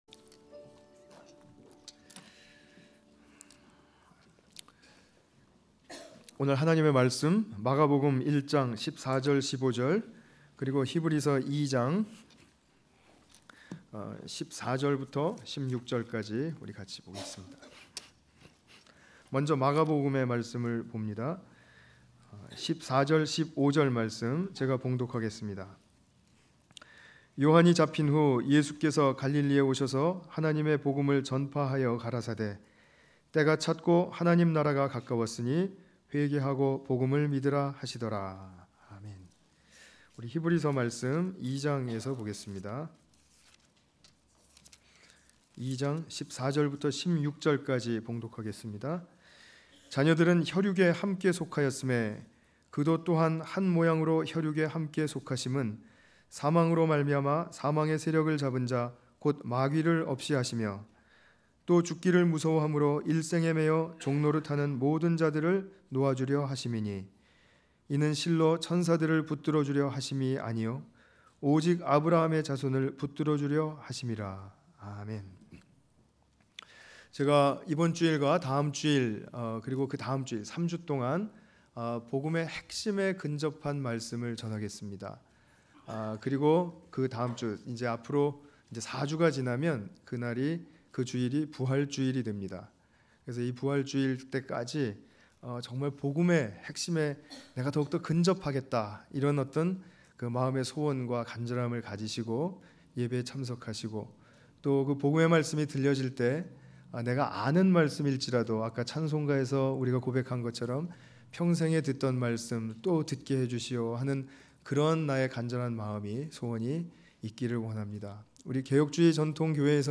히브리서 2장 14-16절 관련 Tagged with 주일예배